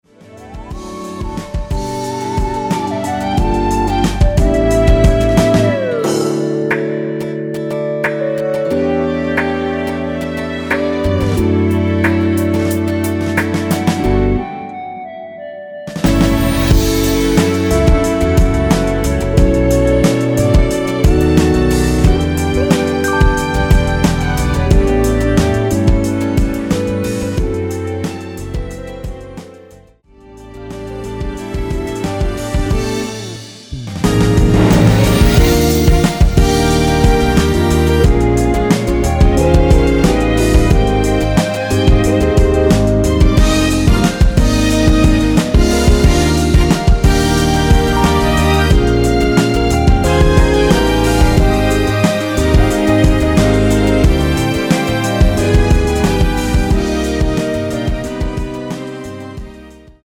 원키에서(-1) 내린 멜로디 포함된 MR입니다.
D
앞부분30초, 뒷부분30초씩 편집해서 올려 드리고 있습니다.
중간에 음이 끈어지고 다시 나오는 이유는